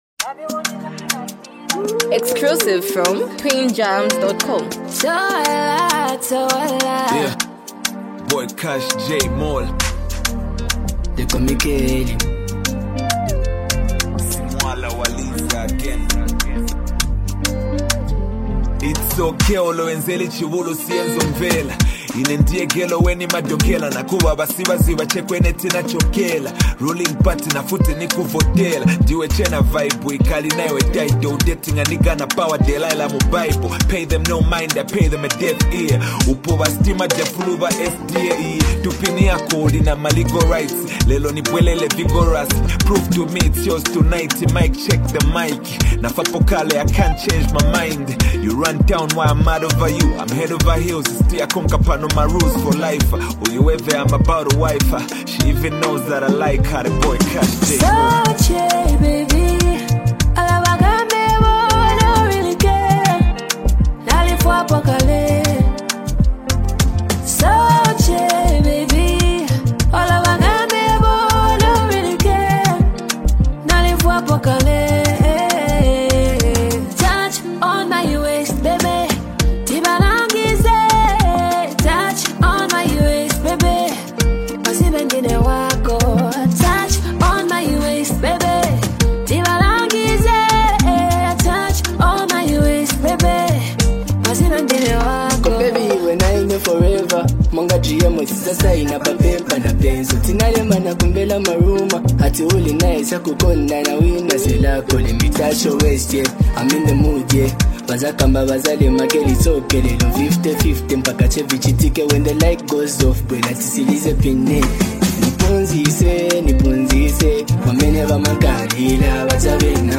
a heartfelt blend of rap and melodic R&B
wrapped in a polished Zambian urban sound.